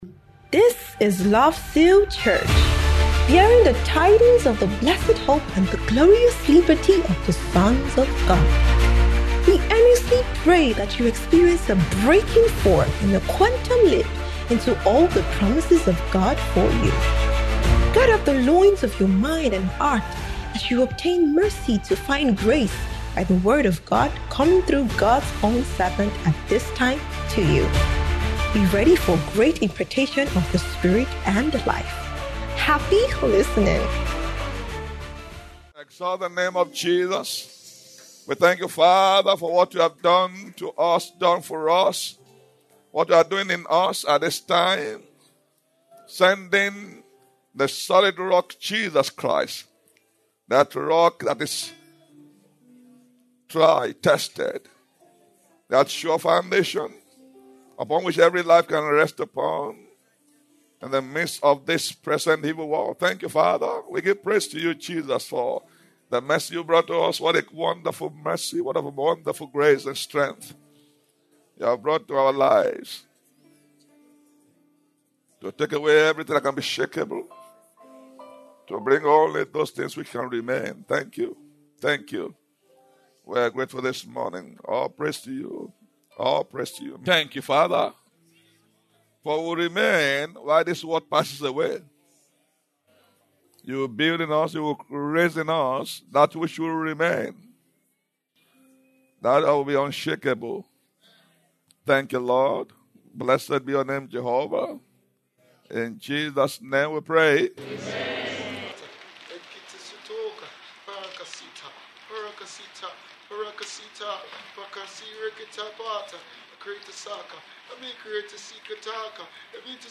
Family & ForeverOne Summit 2025